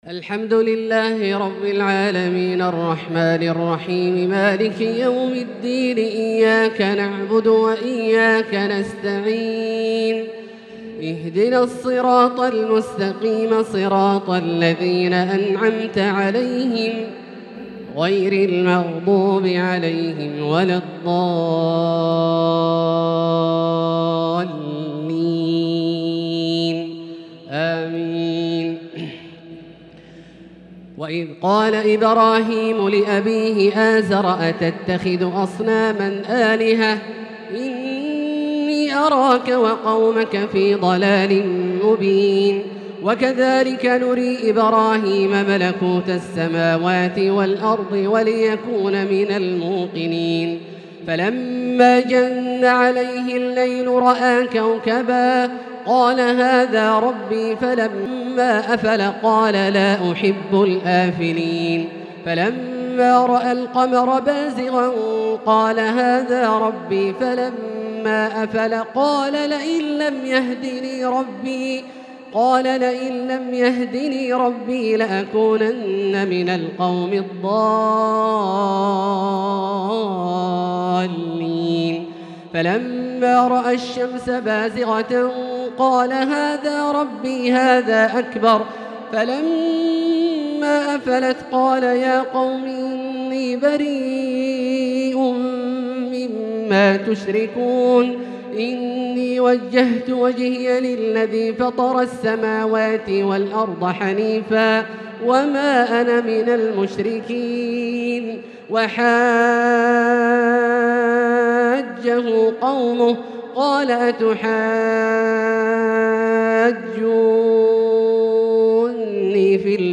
تراويح ليلة 10 رمضان 1443هـ من سورة الأنعام (74-150) |taraweeh 10st niqht ramadan Surah Al-Anaam1443H > تراويح الحرم المكي عام 1443 🕋 > التراويح - تلاوات الحرمين